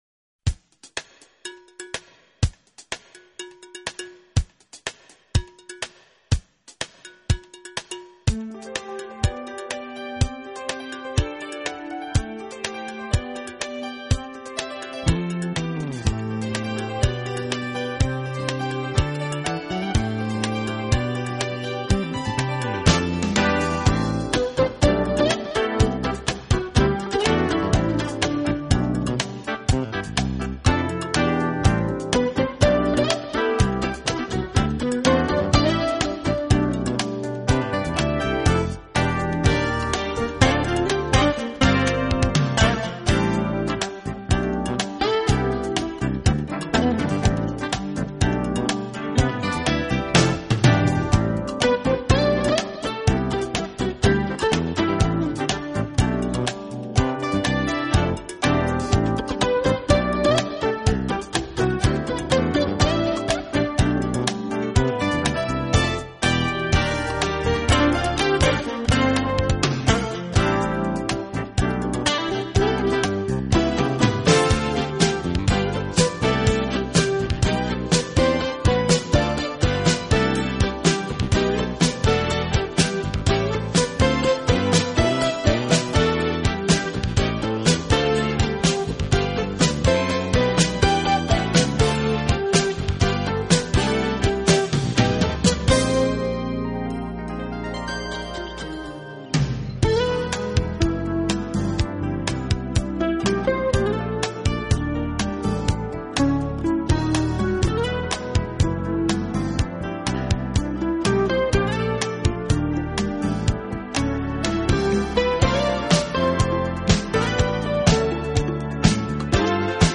Format: Original recording remastered